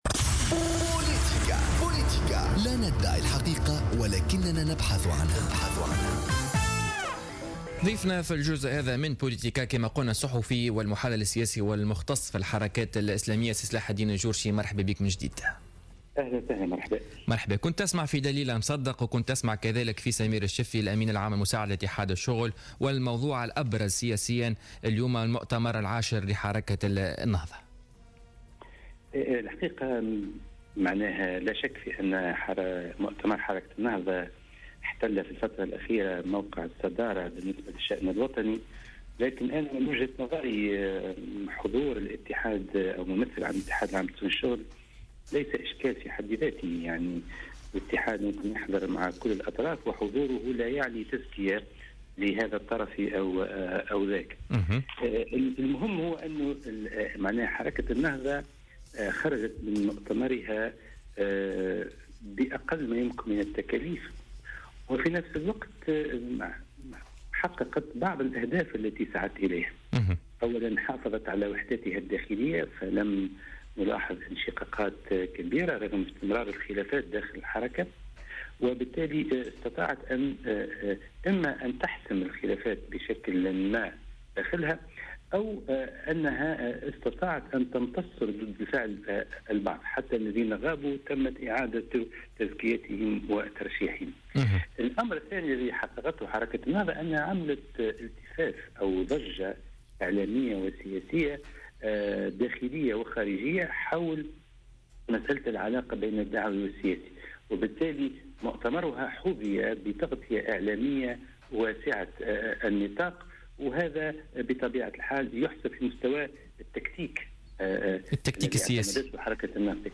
مداخلة له في بوليتيكا